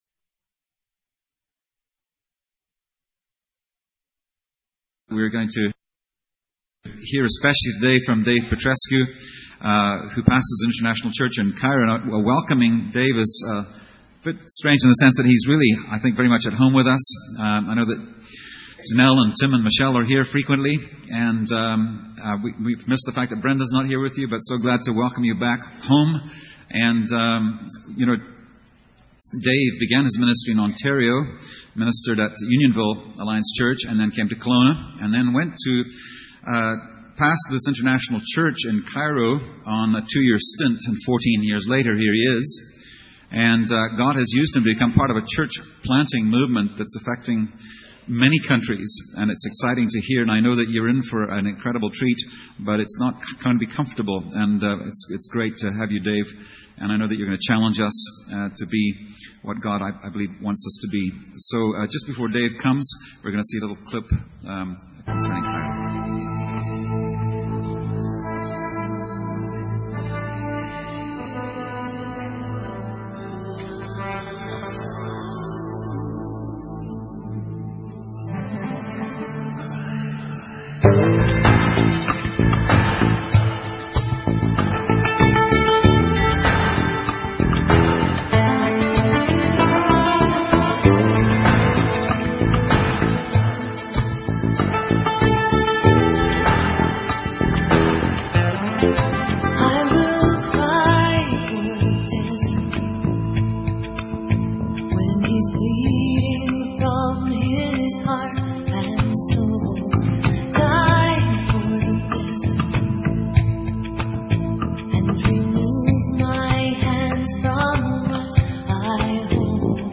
*Note: The exact date for this sermon is unknown.